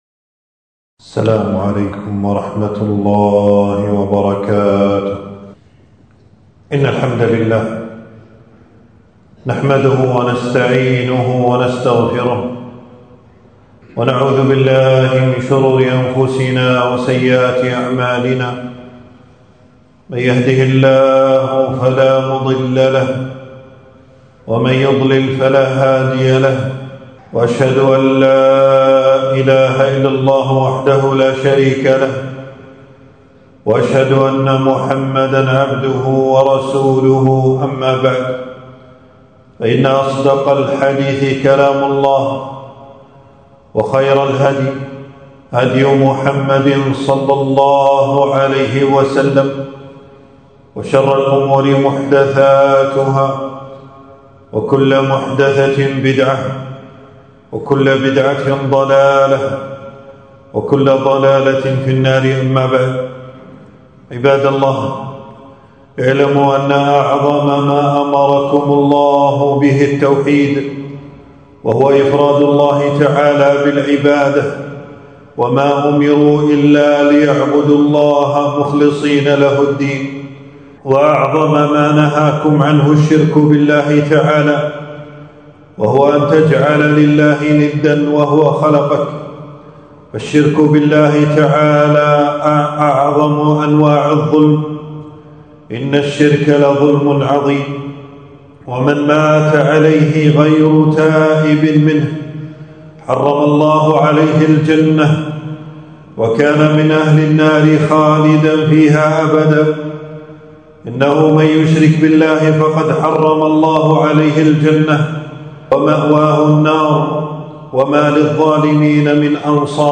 خطبة - أهمية التوحيد وخطورة الشرك ووسائله